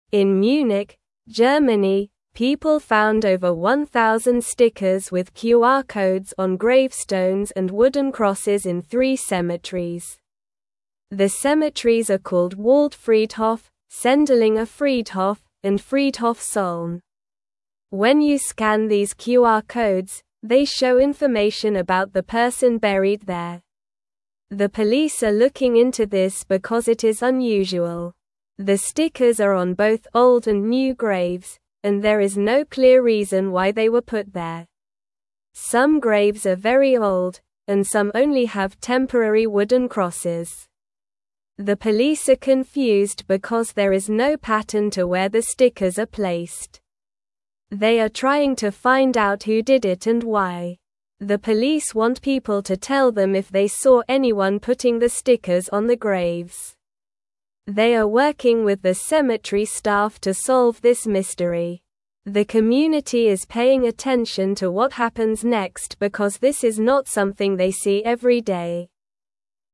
Slow
English-Newsroom-Lower-Intermediate-SLOW-Reading-Stickers-on-Graves-Tell-Stories-of-the-Past.mp3